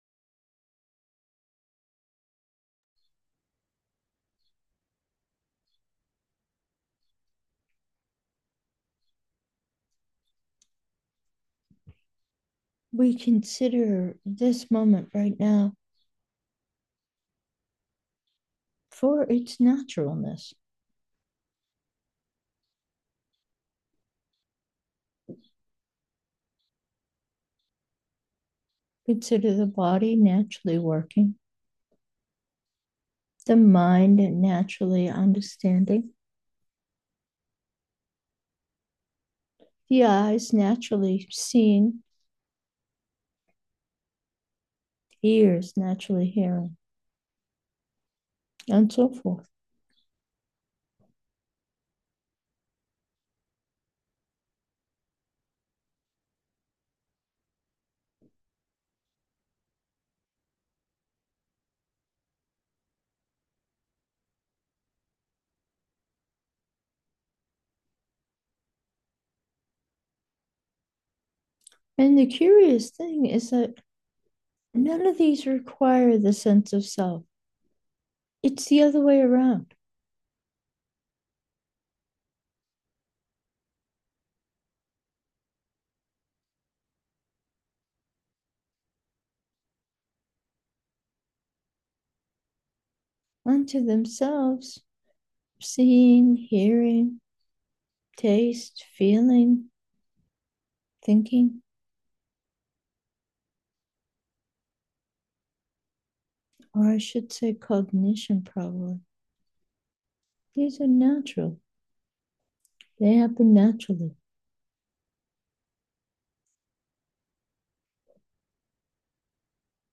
Meditation: peace 3, gentle